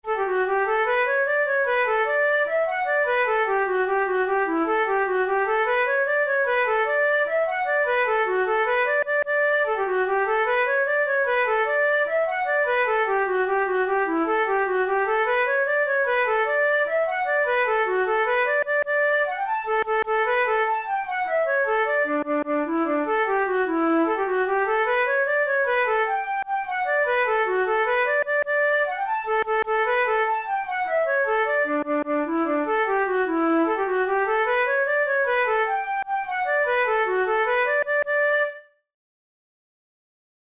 ComposerTraditional Irish
KeyD major
Time signature6/8
Tempo100 BPM
Jigs, Traditional/Folk
Traditional Irish jig